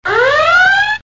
red alert
Category: Sound FX   Right: Personal